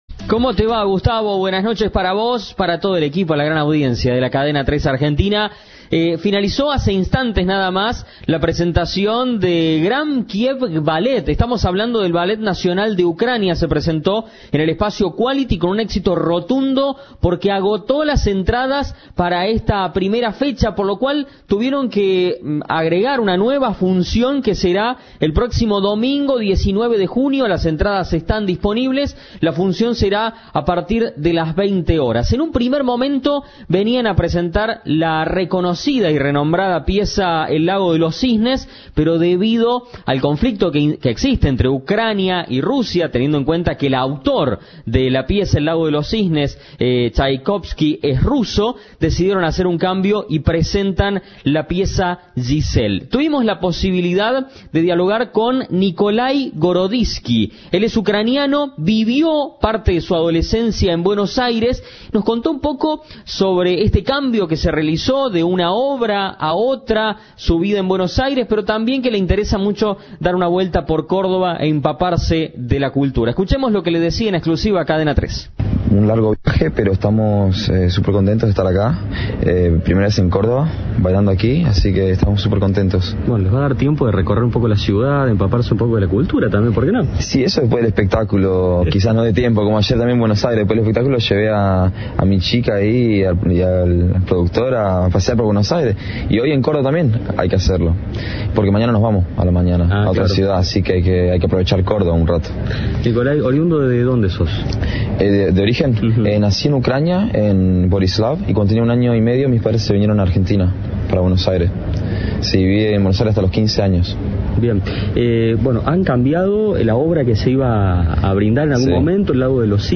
En diálogo con Cadena 3, contó que hicieron varios shows a beneficio del ejército ucraniano por la guerra con Rusia.